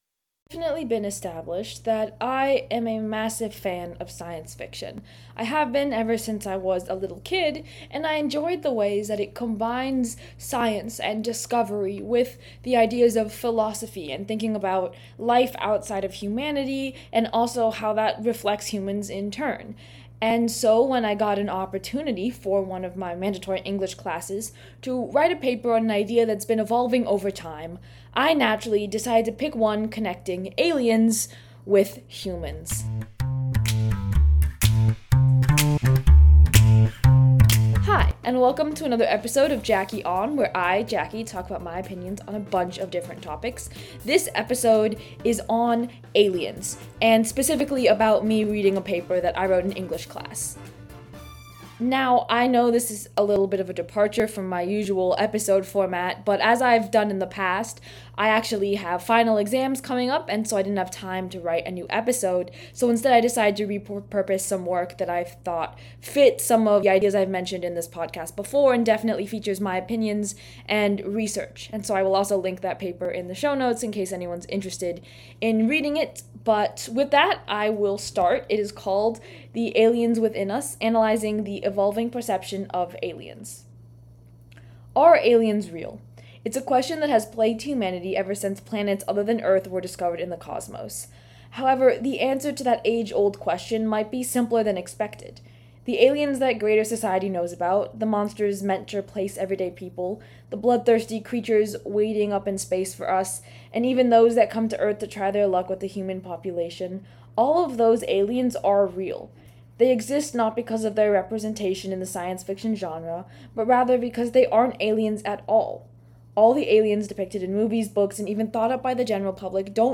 She explores the connection between science fiction, philosophy, and social issues. Small disclaimer: There were slight technical difficulties so the audio quality might change in the middle.